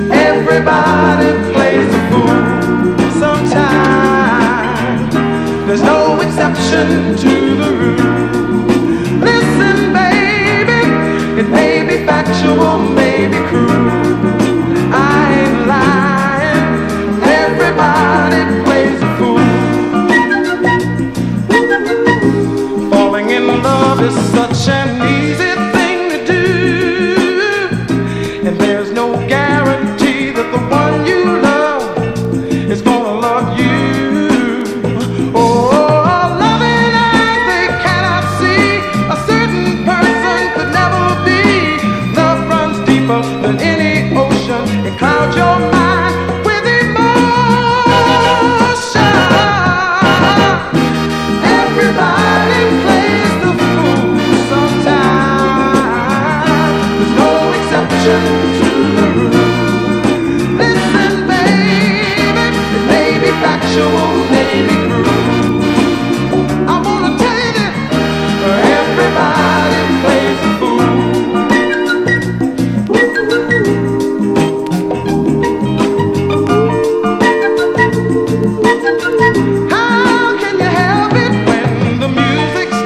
SOUL / SOUL / 70'S～ / LATIN SOUL / FUNK
メロウなヴォーカルのグルーヴィー・ラテン・ファンク